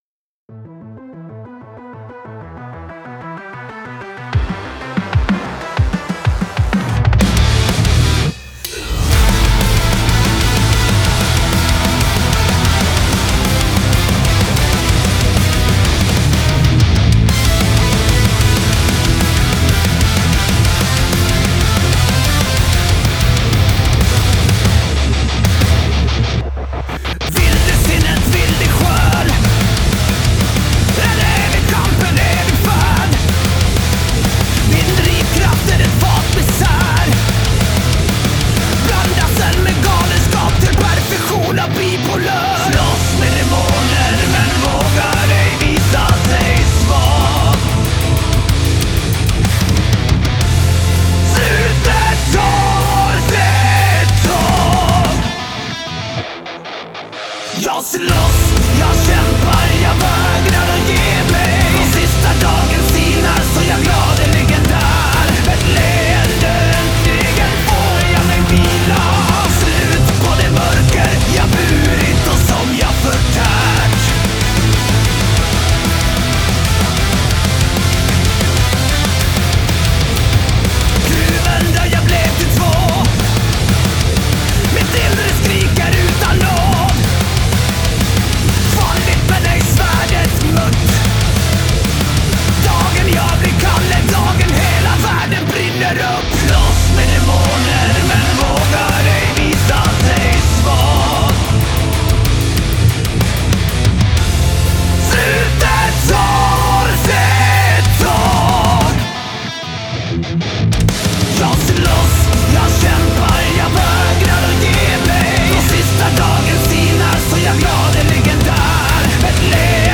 Svensk metal med knutna nävar och blicken framåt.
Genre: Tech metal, industrial, power metal